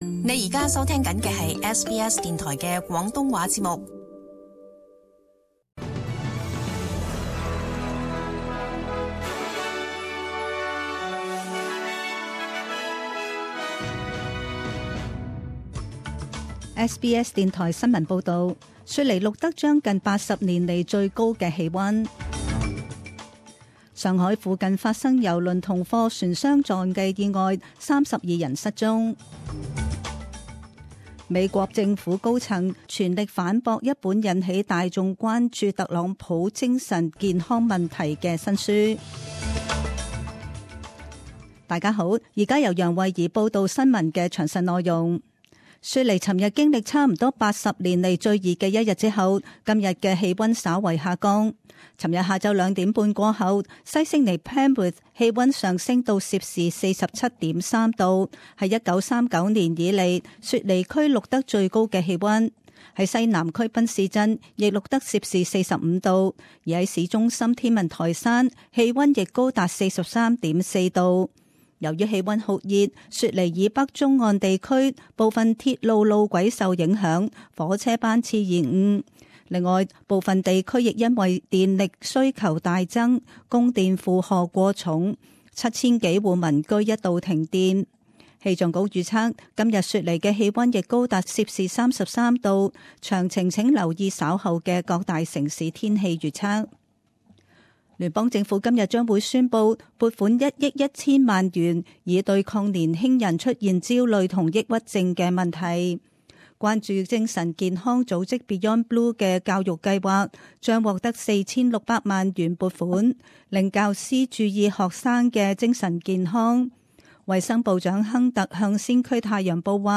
SBS Cantonese 10am news Source: SBS